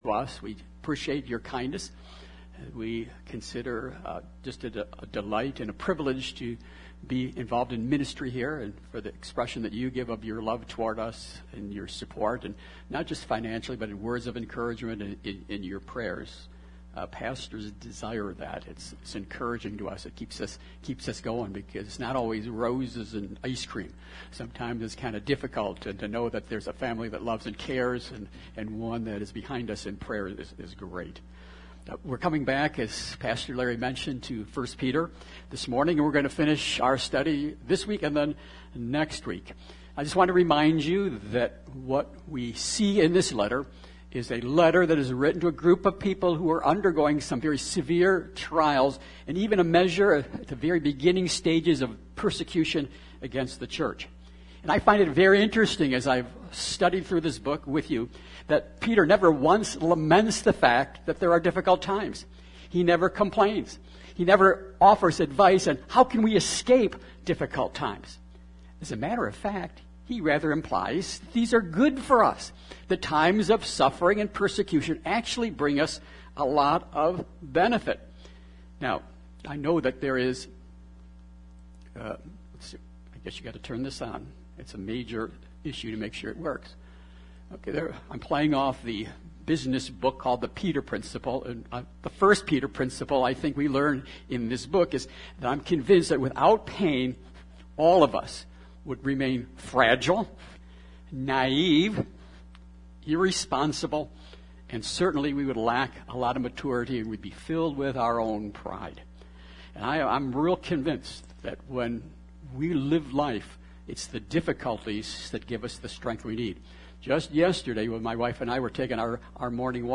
Jan 08, 2023 You’ve Got an Enemy MP3 SUBSCRIBE on iTunes(Podcast) Notes Sermons in this Series 1 Peter 5:8-11 Thank You, Peter!